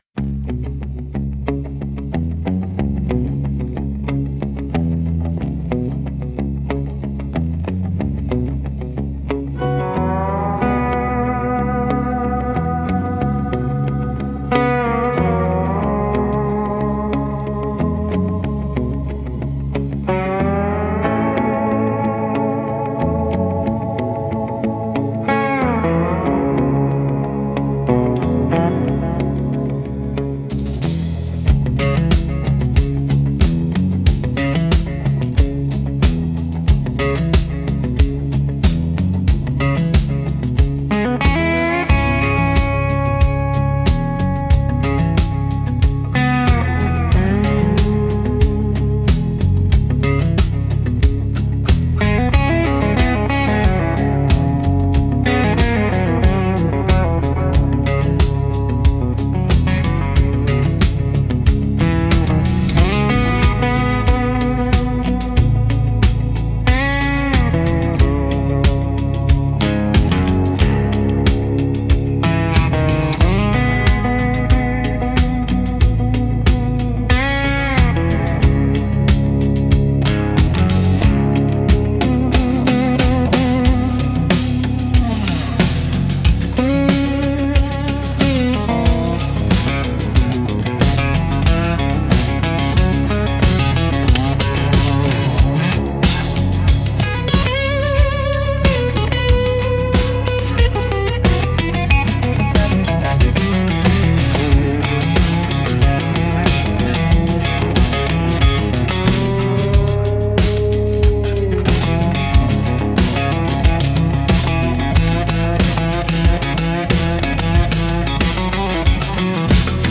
Instrumental - A Murmur in the Bridge 4.24